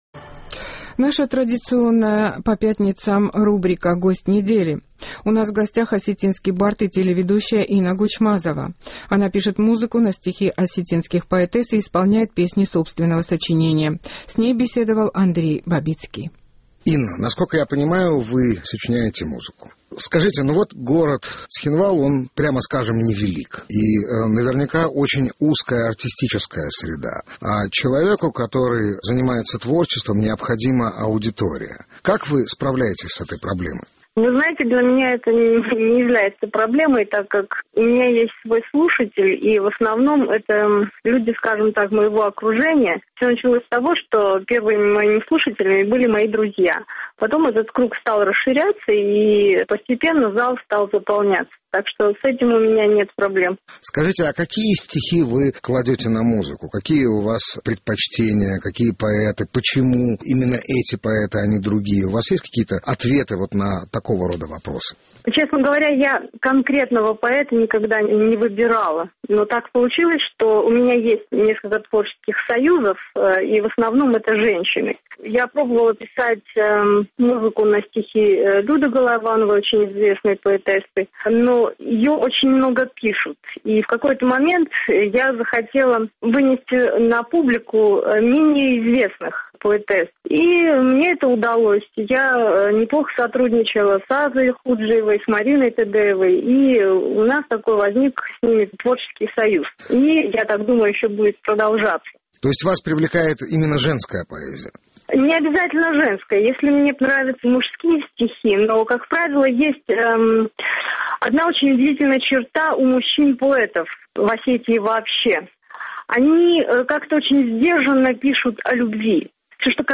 исполняет песни собственного сочинения исключительно на стихи осетинских поэтесс